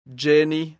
Výslovnost a pravopis
Po kliknutí na příslušné slovo uslyšíte správnou výslovnost, kterou nahlas opakujte.